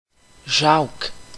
Quebec French: [ʒɑɔ̯k]
Qc-Jacques.ogg.mp3